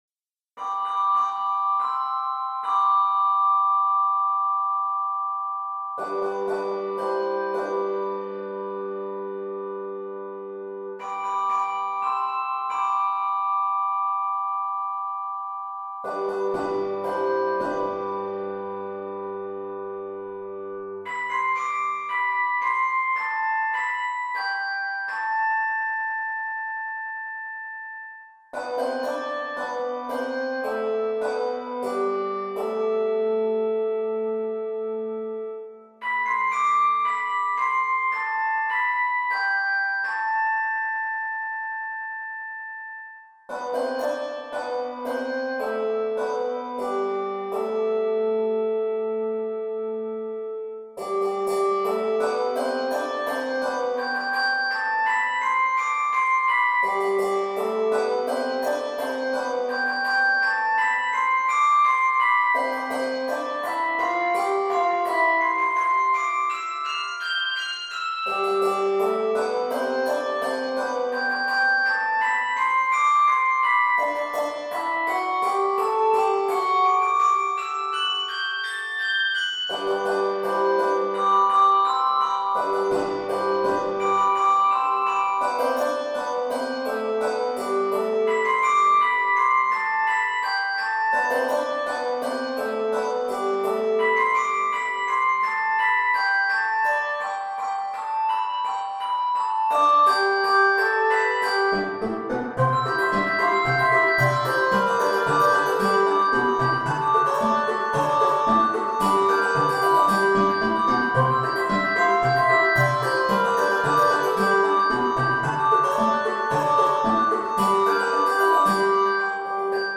This toe-tapping Bluegrass piece